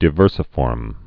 (dĭ-vûrsə-fôrm, dī-)